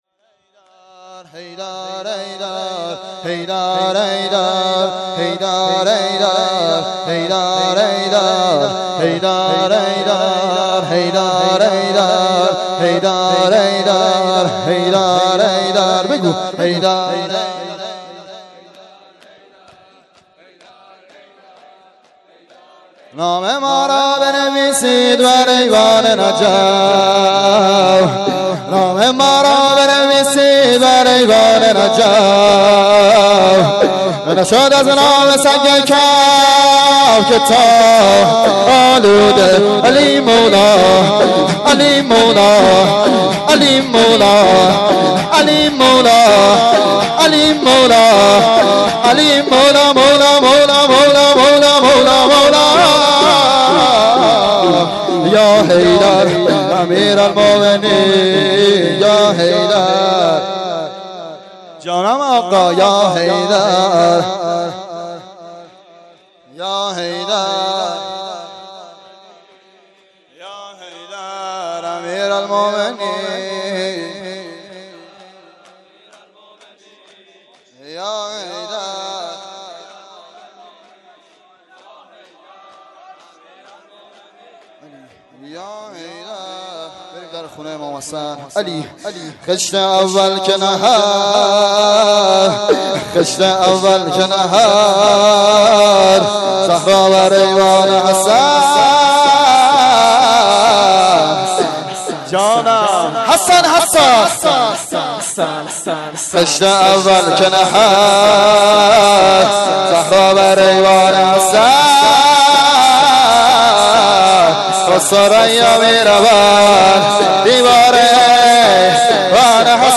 مراسم شهادت حضرت زهرا سلام الله علیها فاطمیه دوم ۱۴۰۳